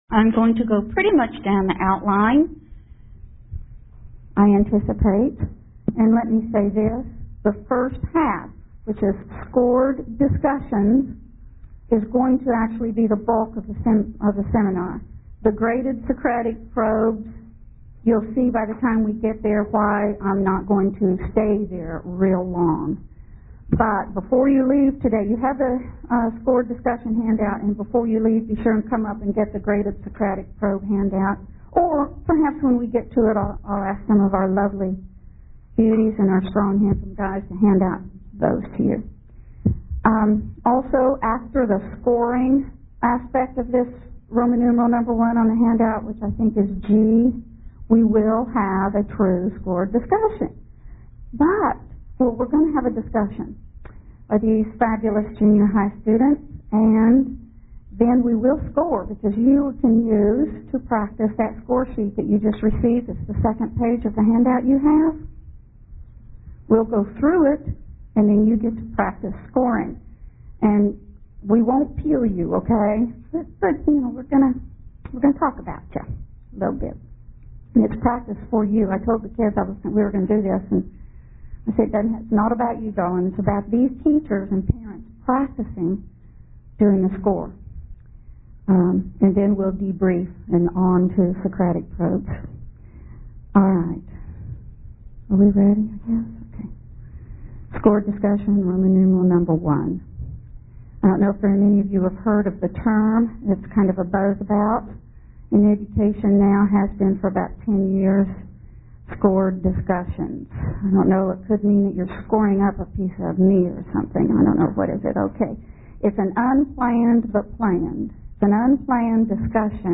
2003 Workshop Talk | 1:00:32 | 7-12, All Grade Levels, History